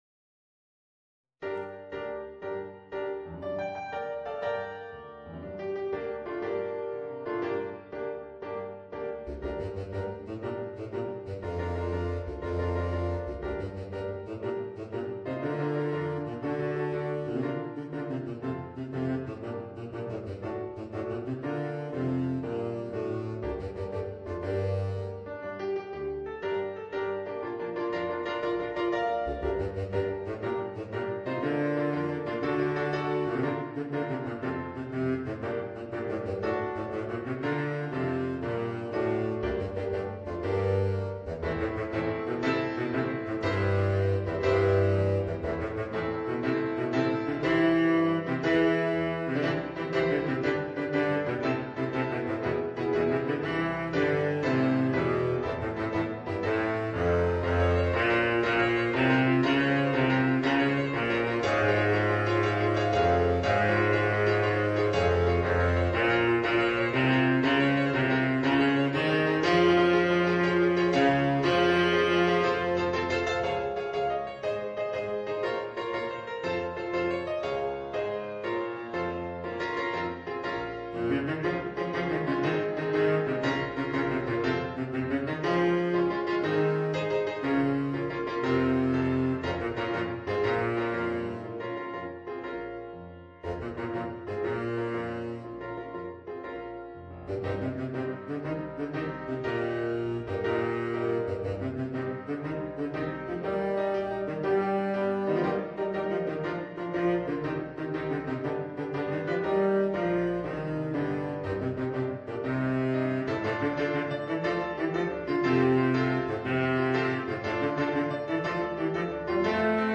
Voicing: Baritone Saxophone and Piano